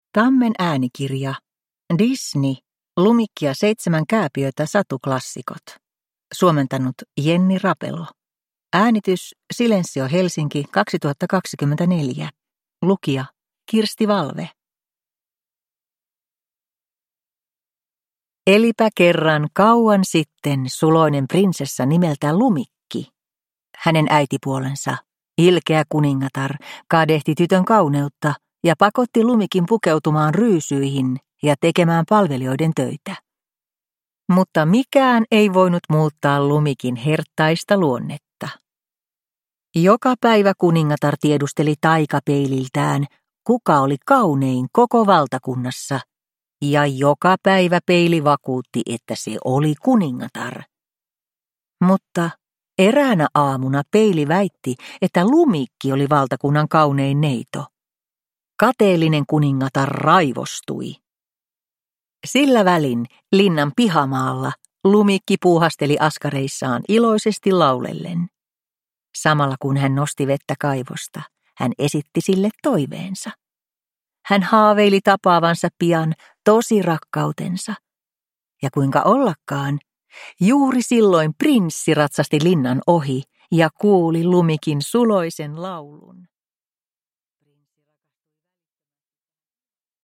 Disney. Lumikki ja seitsemän kääpiötä. Satuklassikot – Ljudbok